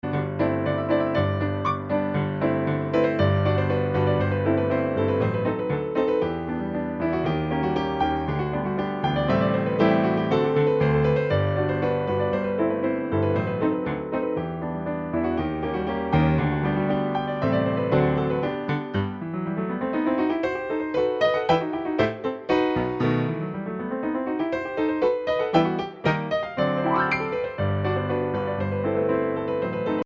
Voicing: 2 Piano 4 Hands